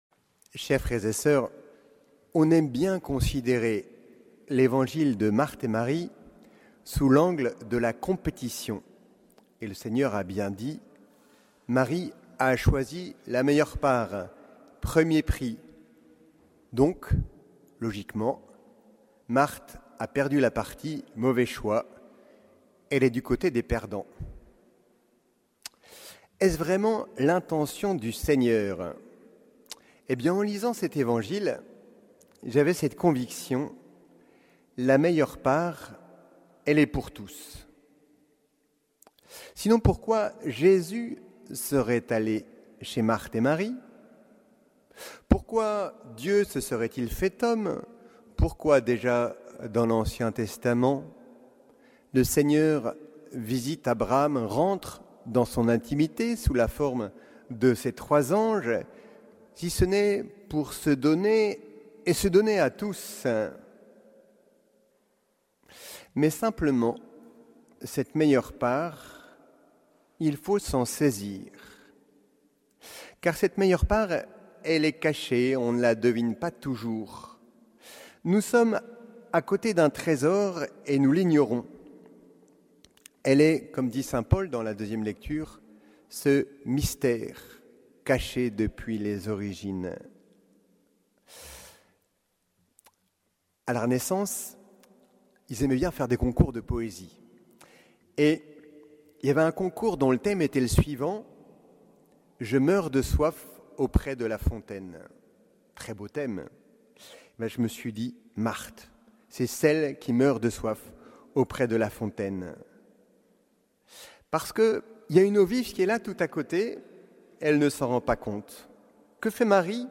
Homélie du 16e dimanche du Temps Ordinaire